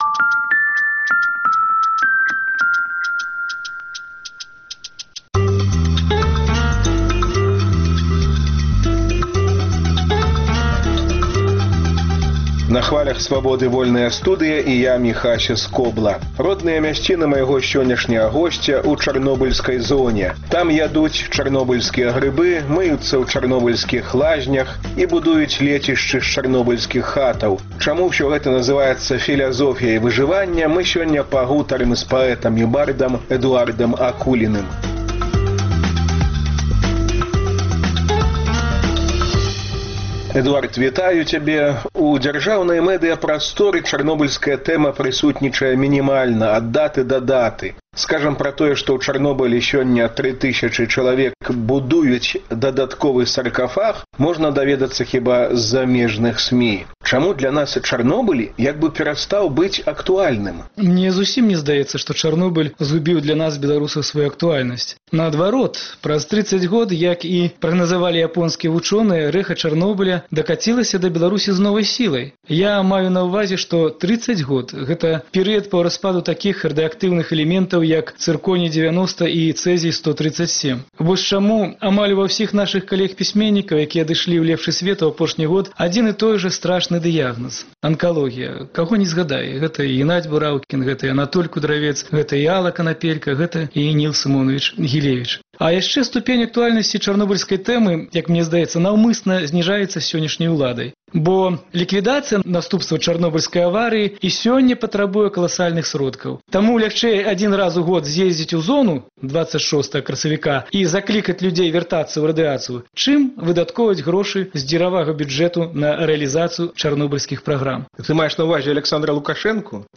Там ядуць чарнобыльскія грыбы, мыюцца ў чарнобыльскіх лазьнях і будуюць лецішчы з чарнобыльскіх хатаў. Чаму ўсё гэта называецца “філязофіяй выжываньня” – у гутарцы з паэтам і бардам.